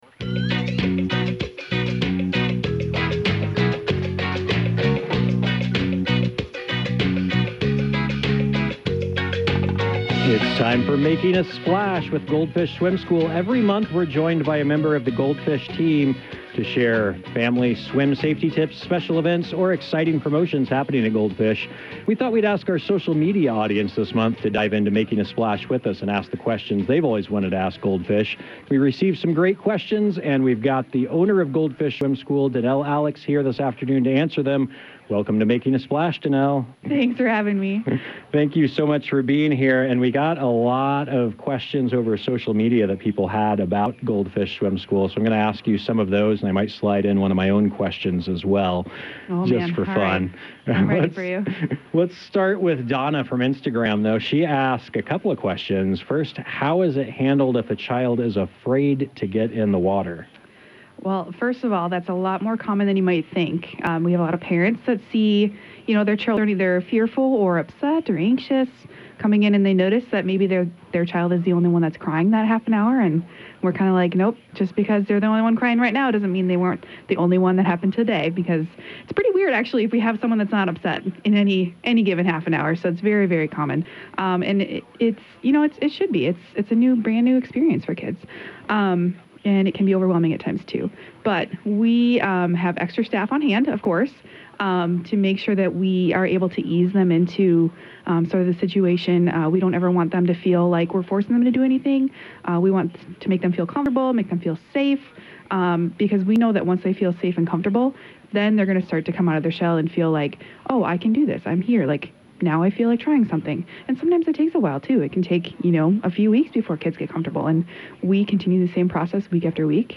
Every month on 97.9 The Hill, we are joined a member of the Goldfish team for a conversation about building confidence in kids, in and out of the pool.
And, of course, more answers can be found in September’s  interview!